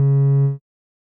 bass
添加三个简单乐器采样包并加载（之后用于替换部分音效）